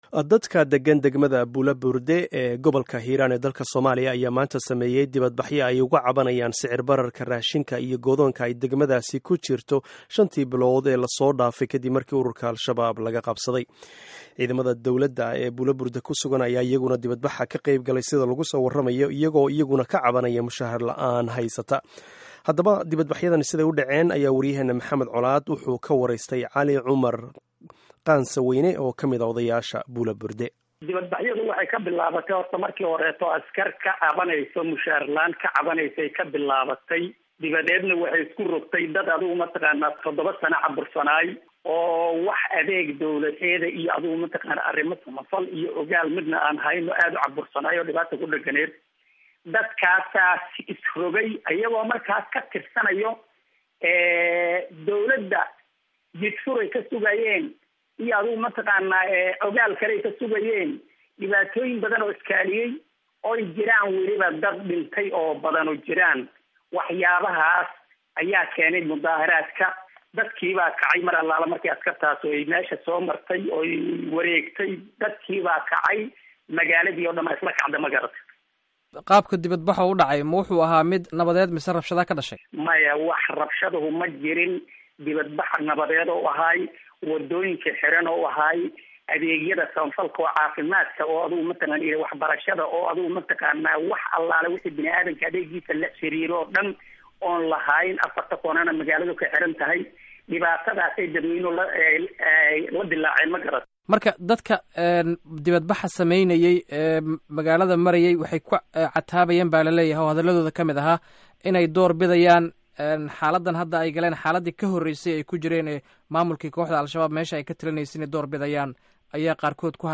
Dhageyso Wareysiga Banaanbaxa Buula-Burde